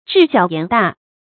智小言大 注音： ㄓㄧˋ ㄒㄧㄠˇ ㄧㄢˊ ㄉㄚˋ 讀音讀法： 意思解釋： 謂才智低下，說話口氣卻很大。